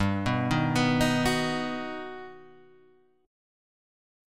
G Augmented Major 7th
G+M7 chord {3 2 1 4 4 2} chord